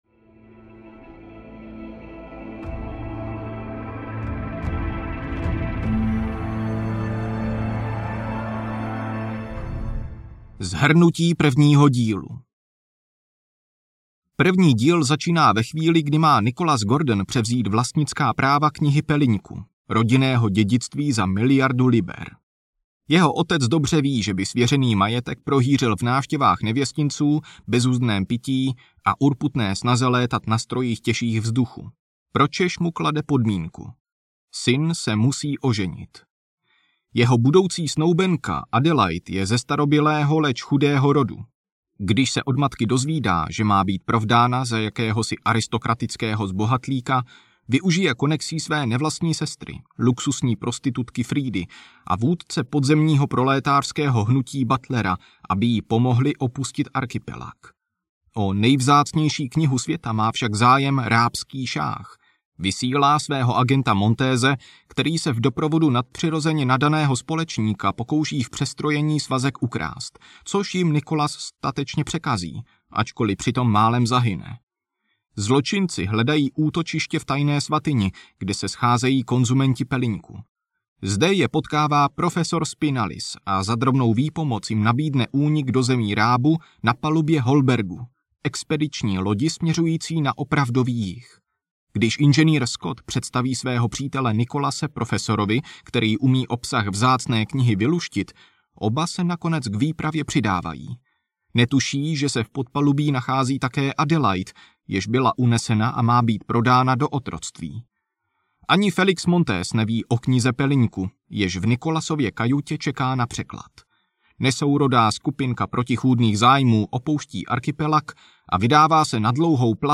Pelyněk: Díl 2 audiokniha
Ukázka z knihy
pelynek-dil-2-audiokniha